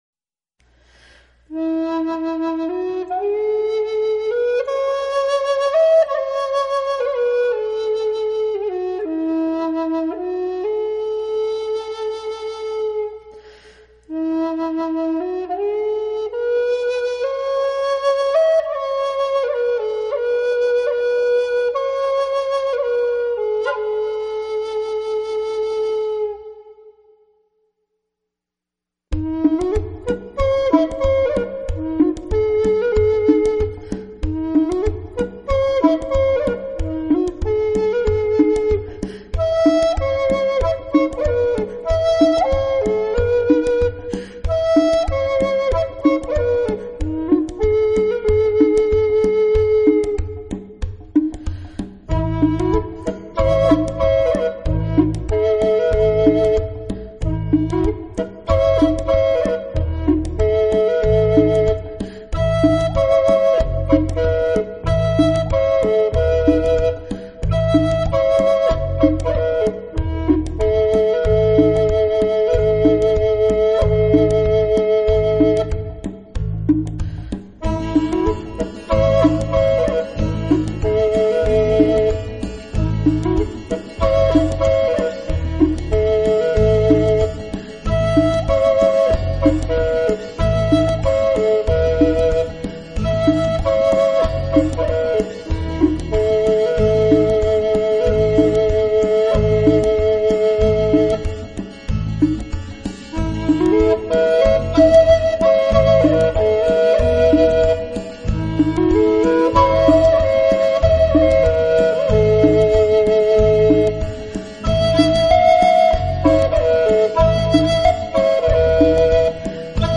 Genre New Age Styles Traditional